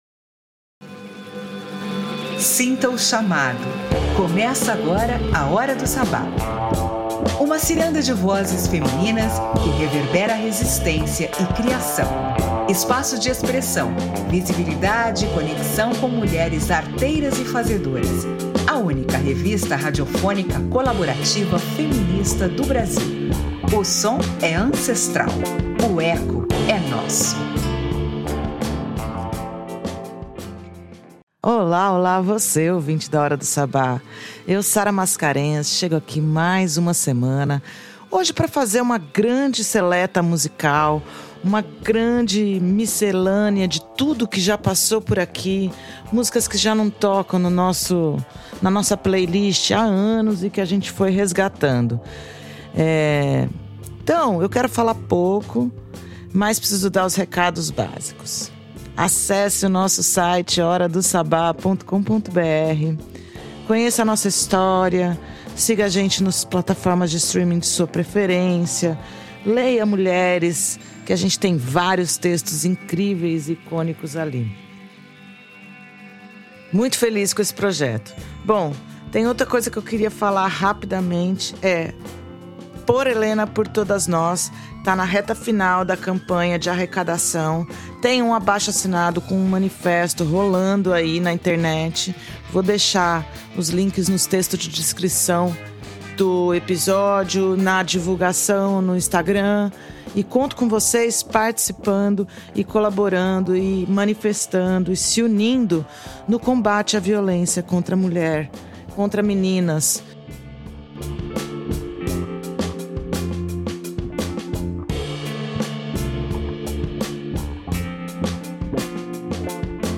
Hora do Sabbat: O Brasil é farto em vozes femininas | AlmA Londrina Rádio Web
A edição 28 apresenta uma Seleta Musical feita para quem escuta com presença.